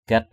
/ɡ͡ɣɛt/ (cv.) gaik =gK 1.